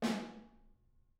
R_B Snare 04 - Room.wav